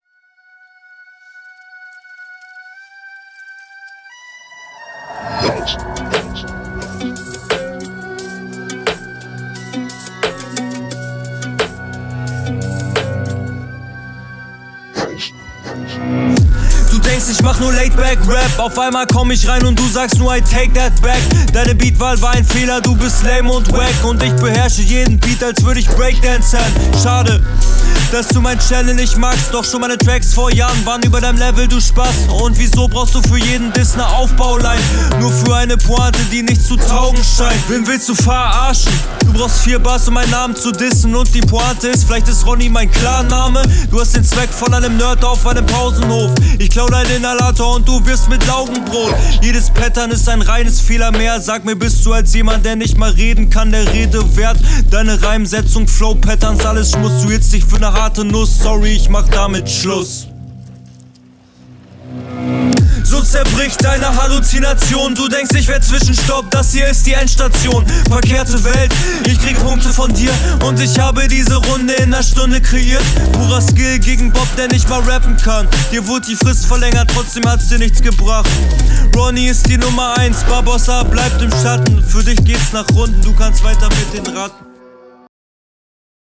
Ich find du kommst richtig gut auf dem Beat.